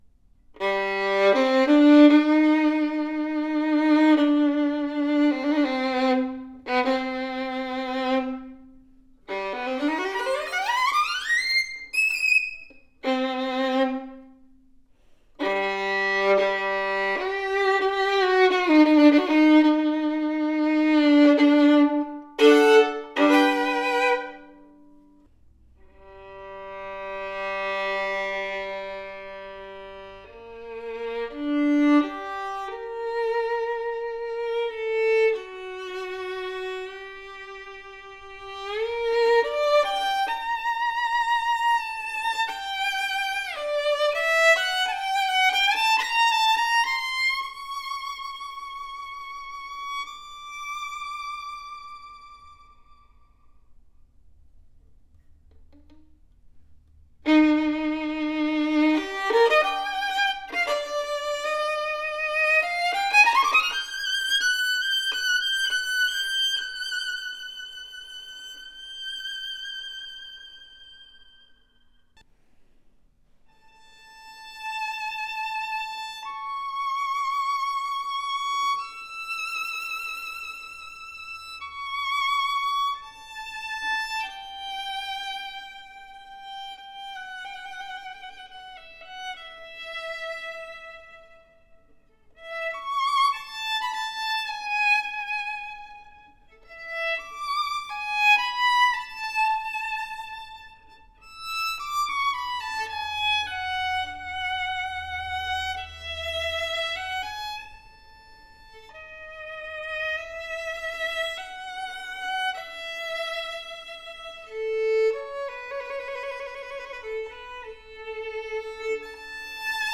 PHENOMENAL tone and workmanship on par with our more expensive violin!
Arguably the darkest and deepest sounding del Gesu due to its large and elongated f hole. Superior bold deep tone with fast response, robust and thick voice that sings with great projection!